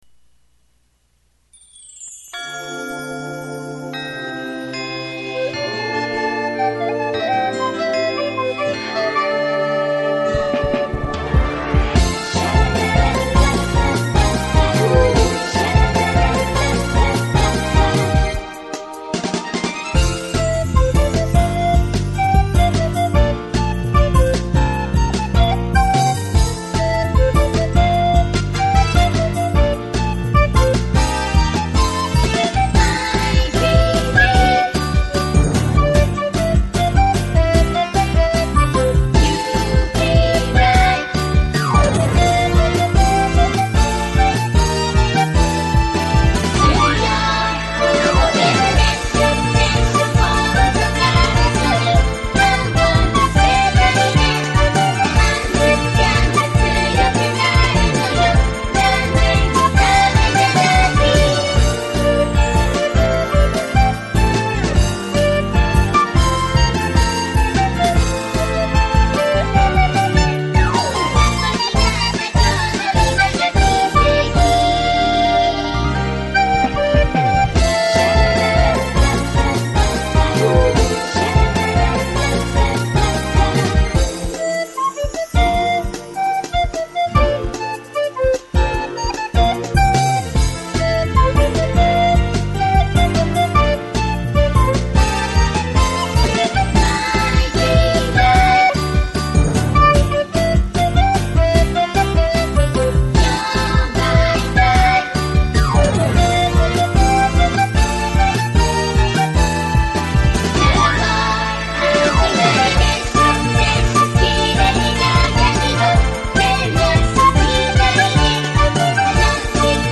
ソプラノリコーダーを買いました。
#や♭を交えて波打つようなメロディーが来ると、ちょっとテンポを速くしただけでミスを連発します。
ミスが多いですが、２時間半の練習成果を上げてみました。
精密採点２で45点～55点ぐらいの演奏レベルです。